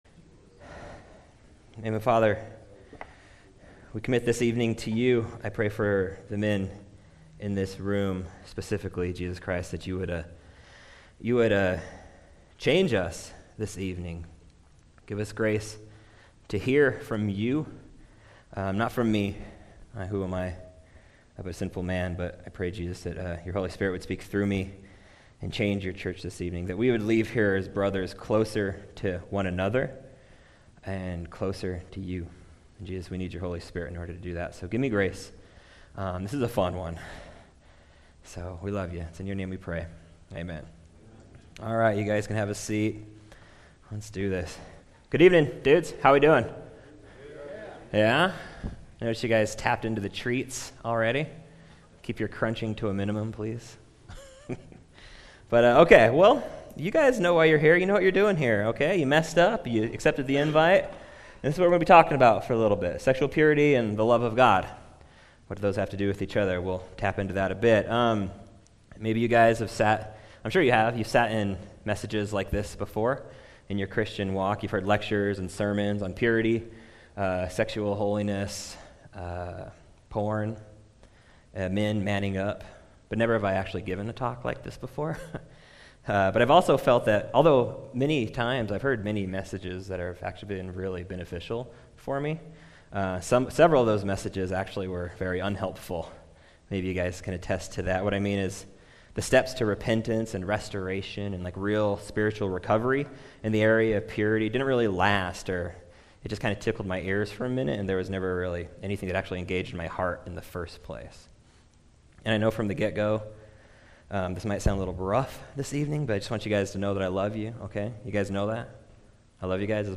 A message from the series "Your Love Remains."